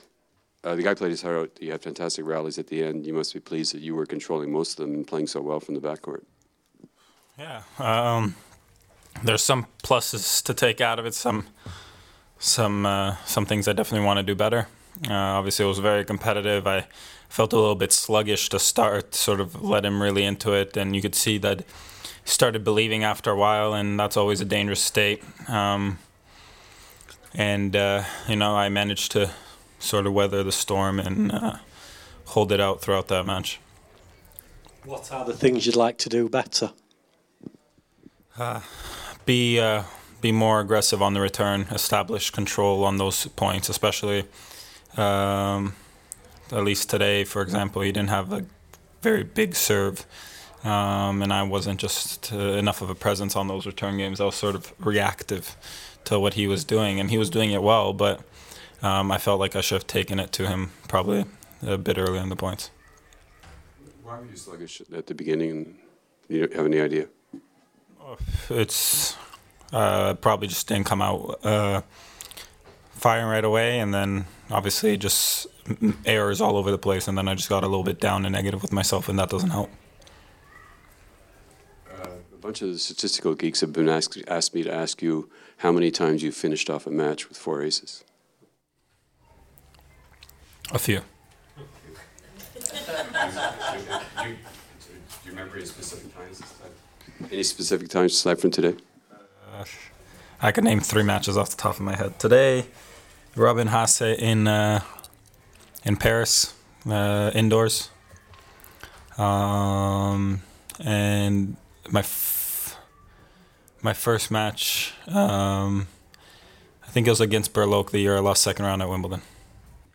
Here's Milos after his 4-6, 6-2, 6-3, 6-4 2ns round win at Roland-Garros vs Rogerio Dutra Silva